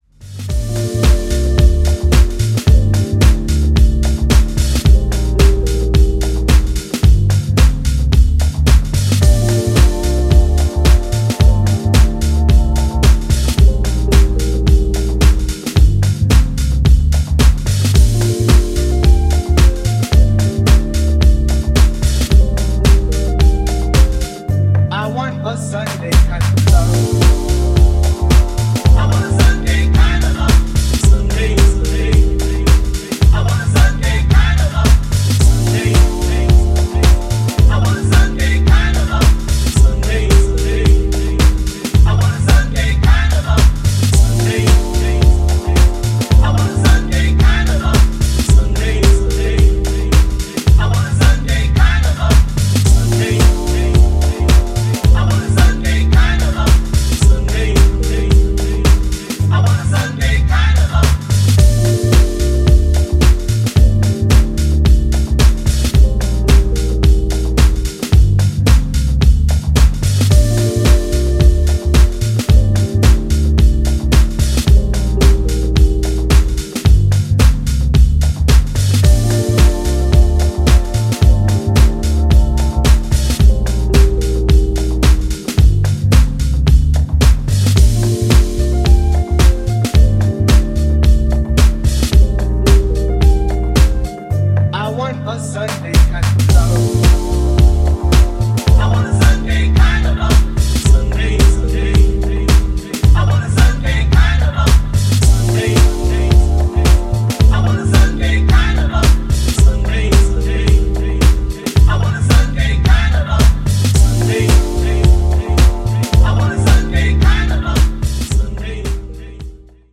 ジャンル(スタイル) DEEP HOUSE / HOUSE / DETROIT